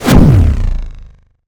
sci-fi_shield_power_deflect_boom_01.wav